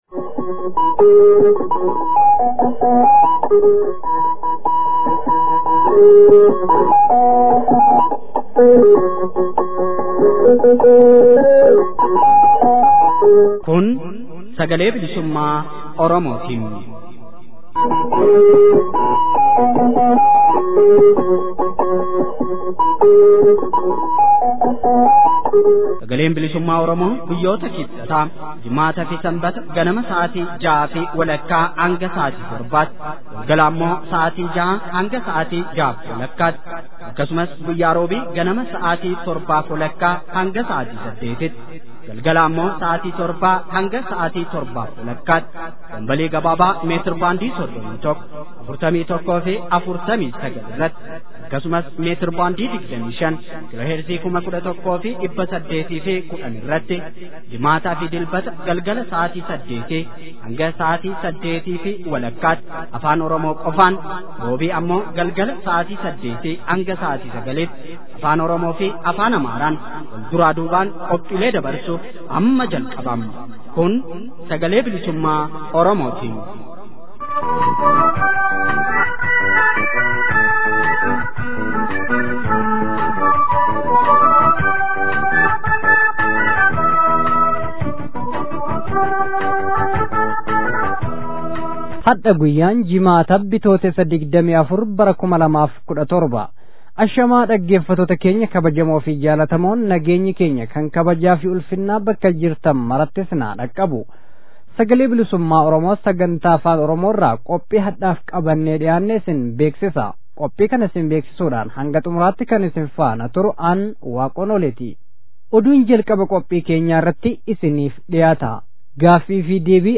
SBO: Bitootessa 24 bara 2017. Oduu, Gaaffii fi Deebii